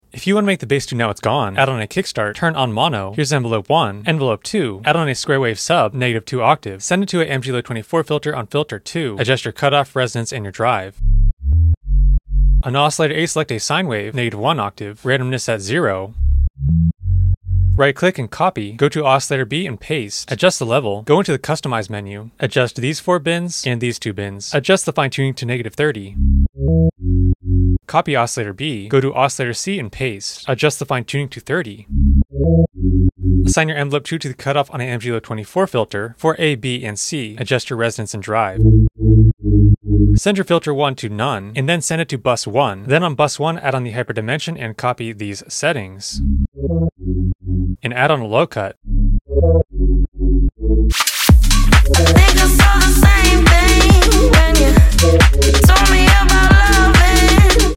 Bass in Serum 2
serum 2 tutorial